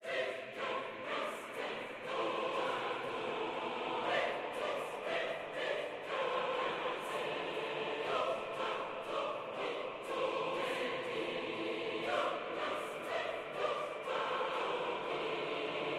一个非常糟糕的合唱团
Tag: 120 bpm Cinematic Loops Choir Loops 2.69 MB wav Key : Unknown FL Studio